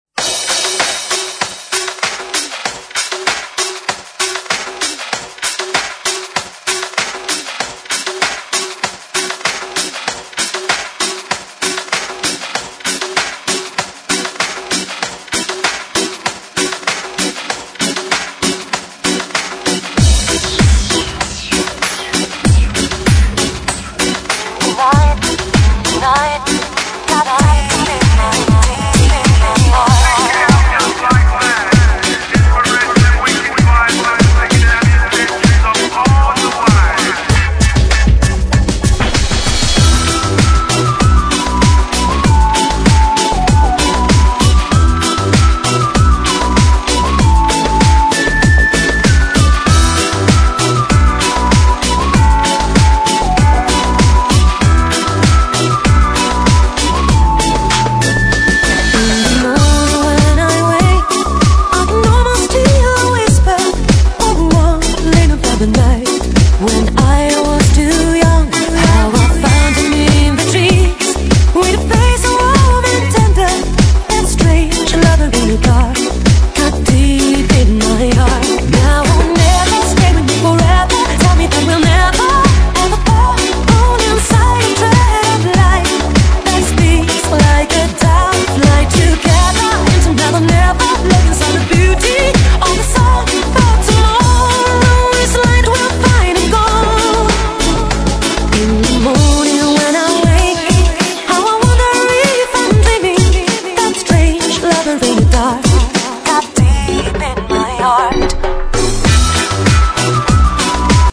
Reggae Dance Edit
※試聴は音質を落しています。